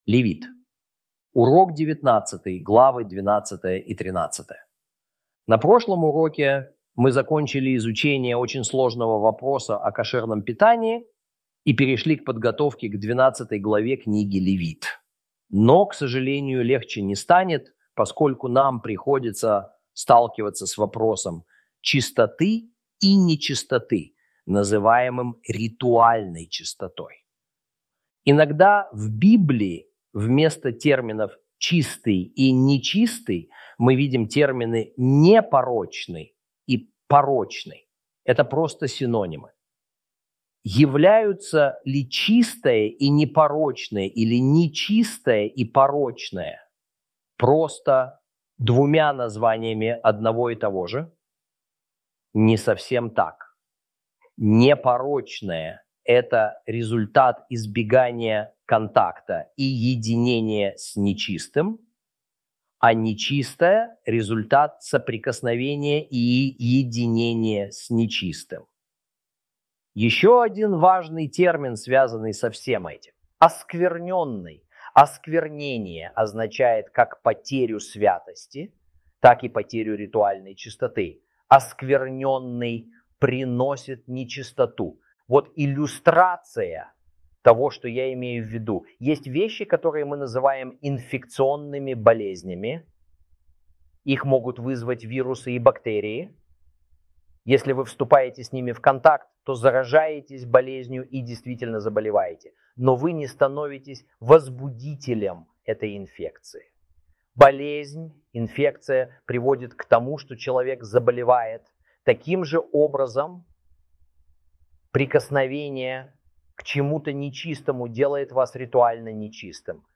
Video, audio and textual lessons